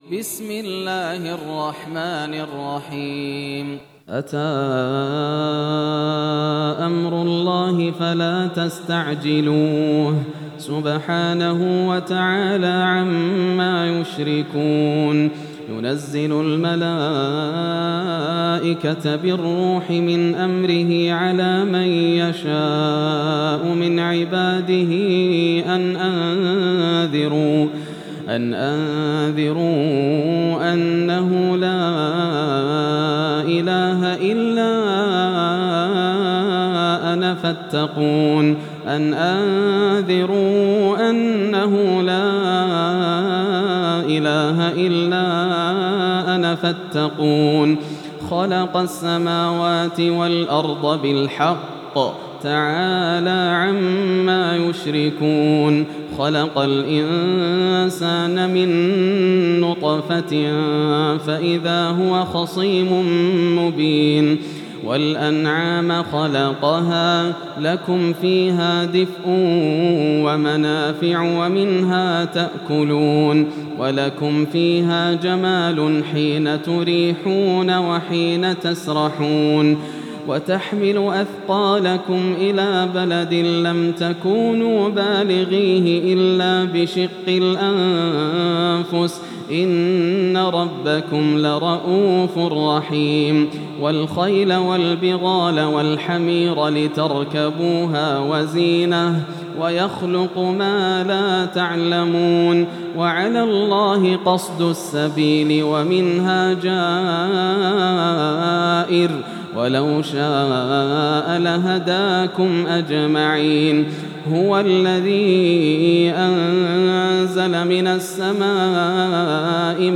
سورة النحل > السور المكتملة > رمضان 1433 هـ > التراويح - تلاوات ياسر الدوسري